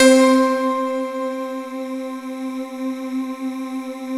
Index of /90_sSampleCDs/Syntec - Wall of Sounds VOL-2/JV-1080/SMALL-PIANO
BELLS PNO LM 3.wav